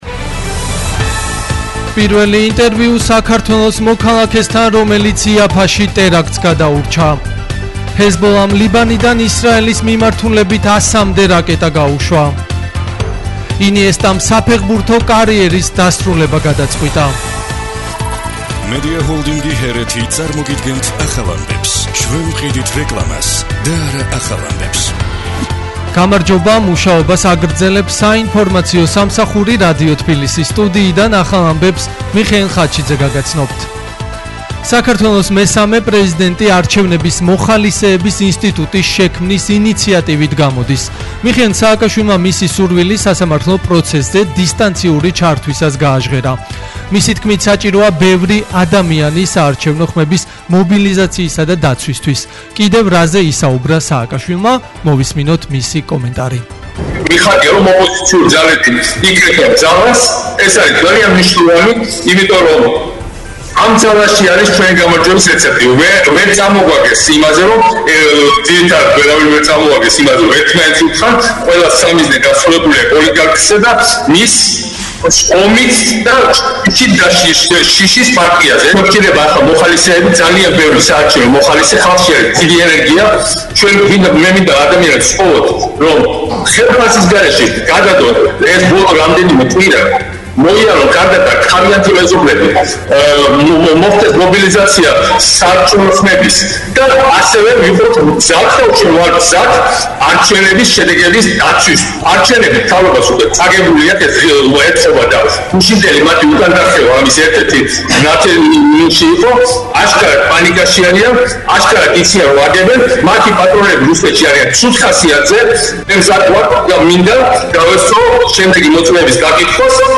ახალი ამბები 15:00 საათზე